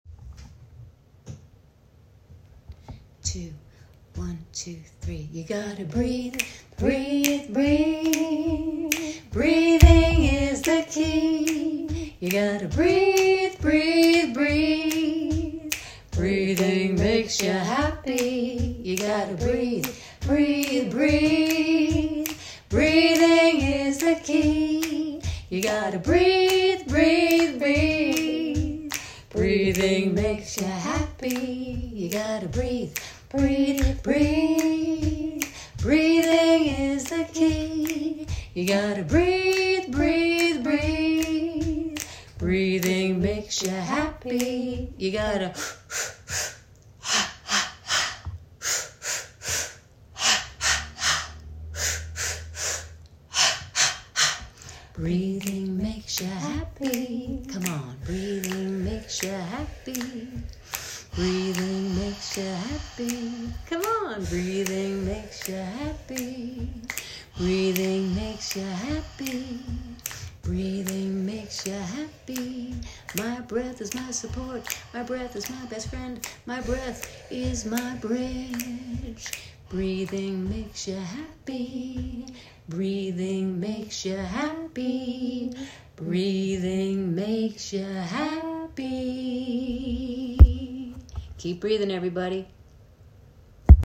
Free Shamanic AND INSPIRATIONAL Music